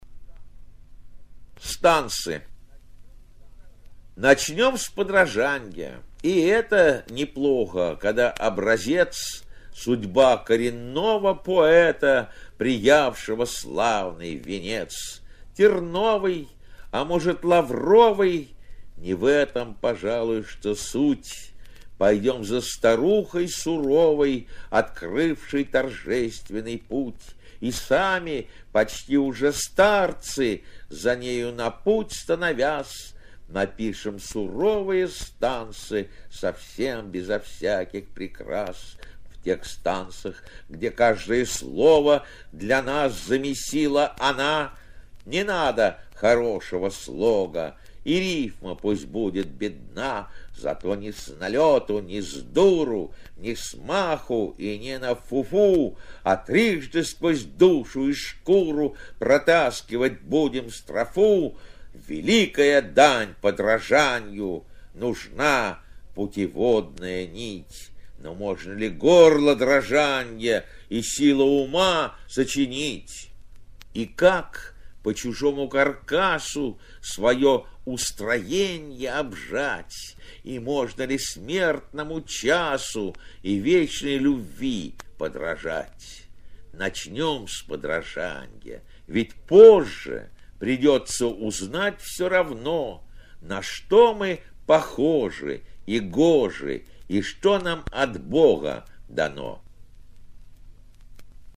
Samoylov-Stansy-chitaet-avtor-stih-club-ru.mp3